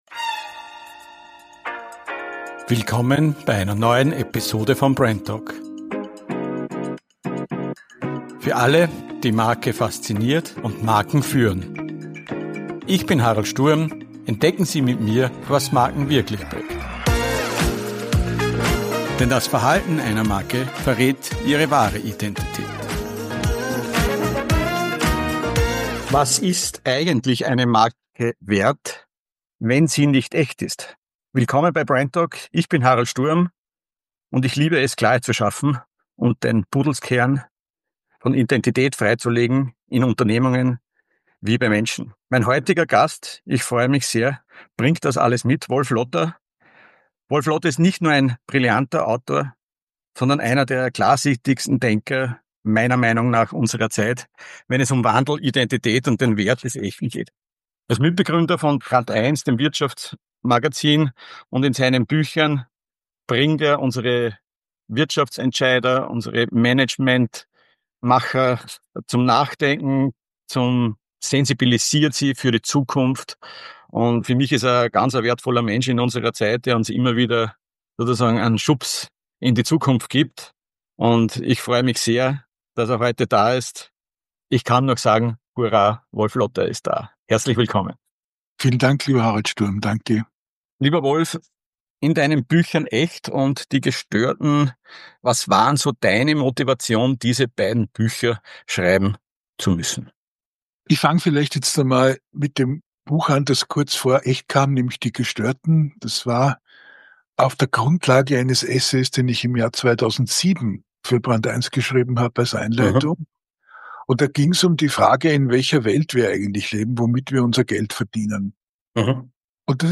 Freu dich auf ein inspirierendes Gespräch über Zukunft, Leadership, Unternehmenskultur, Wissensökonomie – und warum geistige Beweglichkeit heute der wichtigste Wettbewerbsvorteil ist.